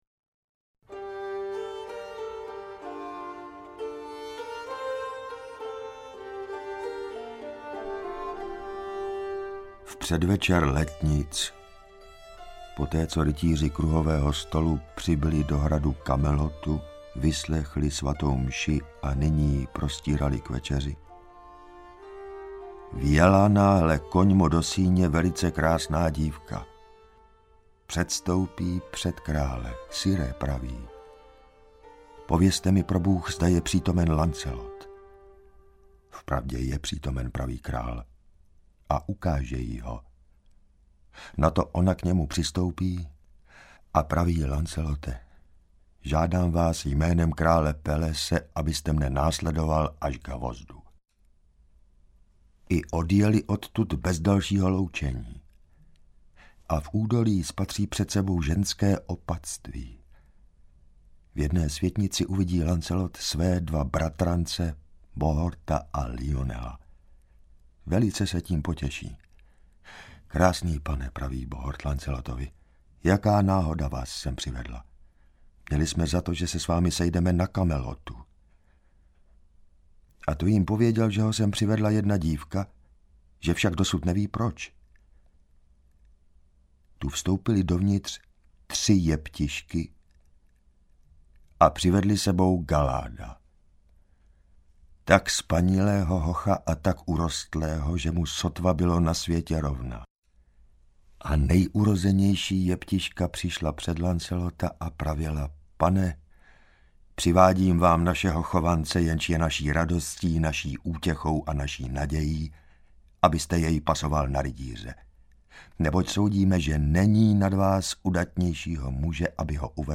Interpret:  Jan Hartl
Mýtický příběh z doby ušlechtilých rytířů, magických mečů, spravedlivého krále Artuše a statečného Lancelota. Četba na pokračování ze čtvrté části rozsáhlého cyklu středověkých prozaických románů, vzniklých v letech 1215–1235.